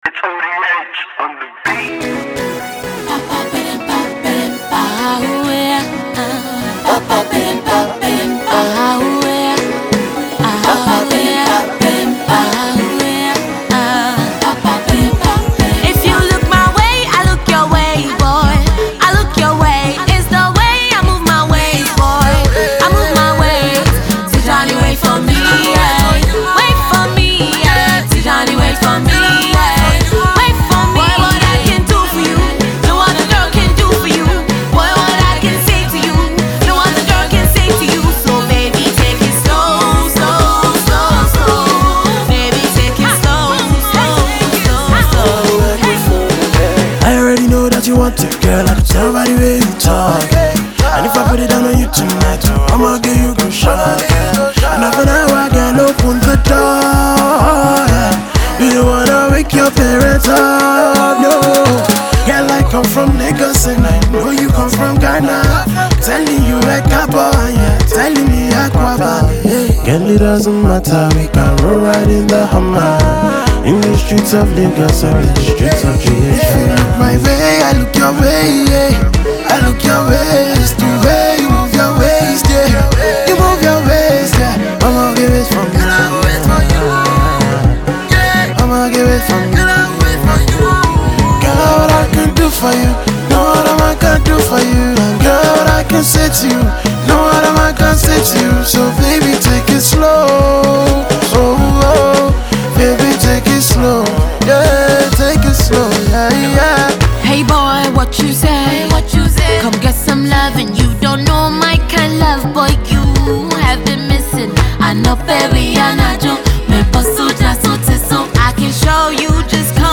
hit-making husky crooner of the Nigerian music industry